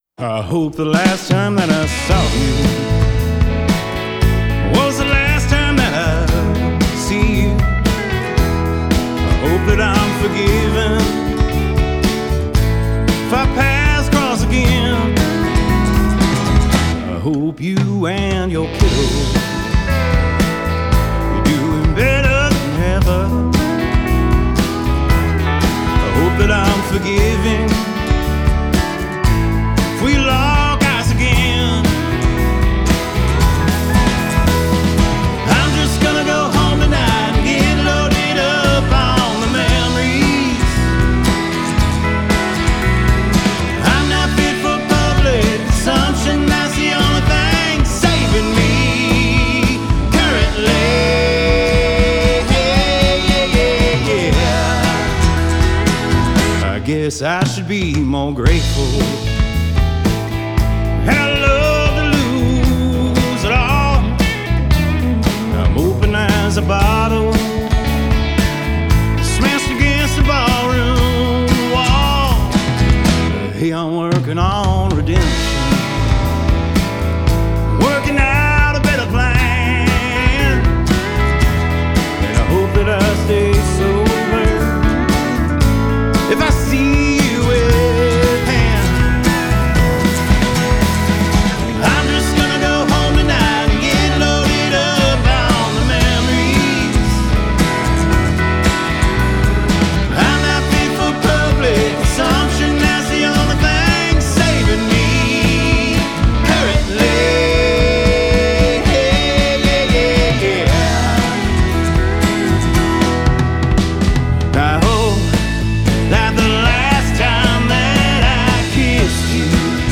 Anchored by a driving rhythm and soaring pedal steel